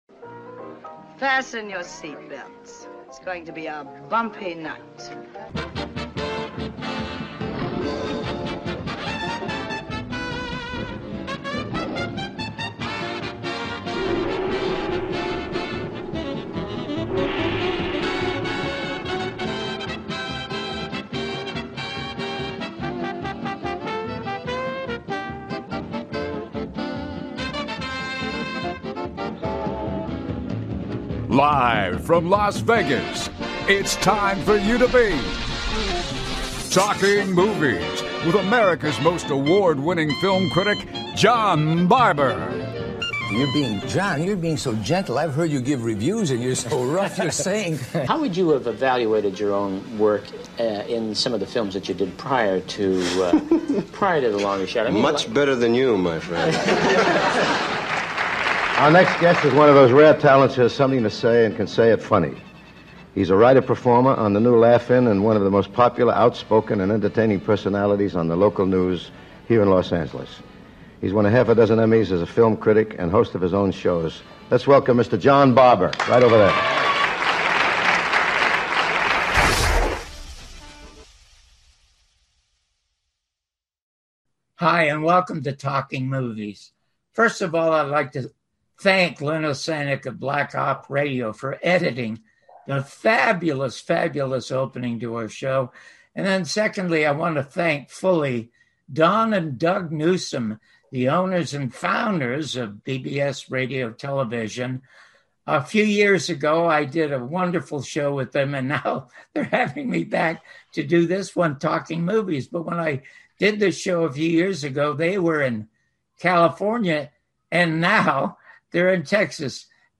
Guest, Eddie Muller, non-fiction writer and founder and president of the Film Noir Foundation
Talking Movies with John Barbour